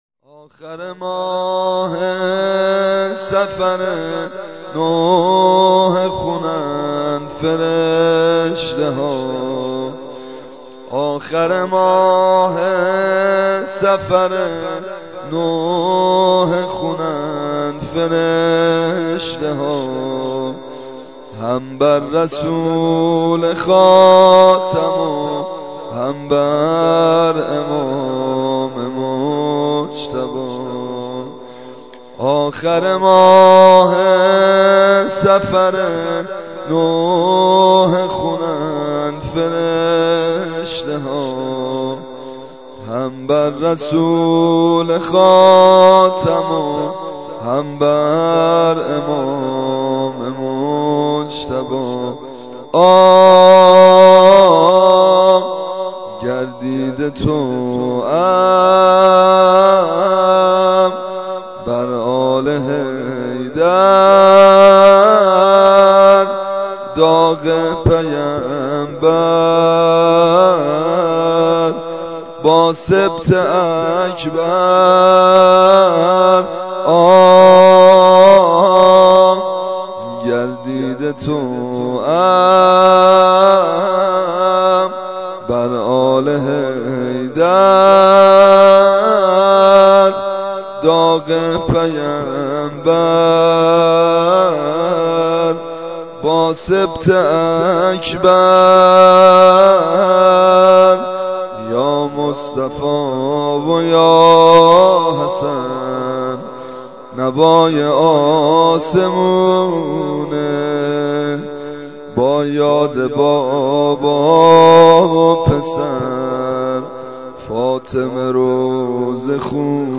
زمزمه - واحد